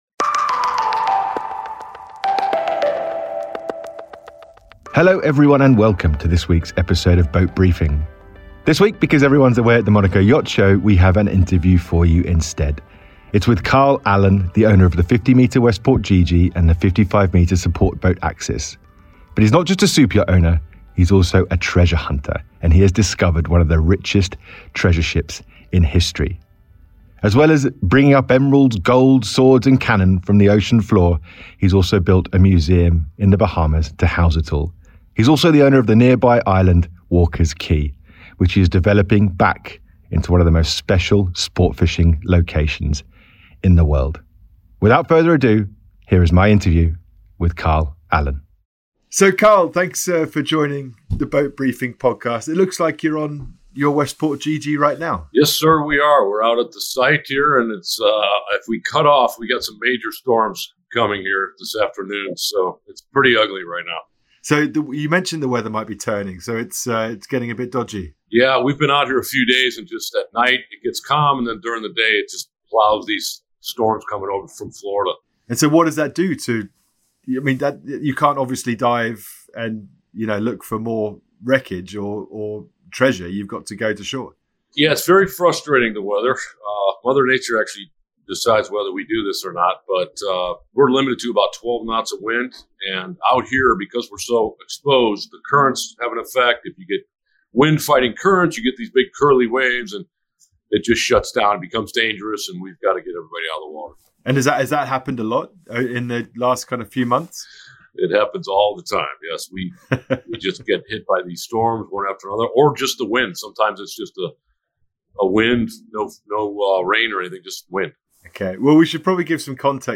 BOAT Briefing / The Big BOAT Interview